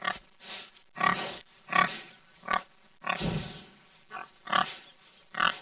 Звуки свиньи
Звуки хряка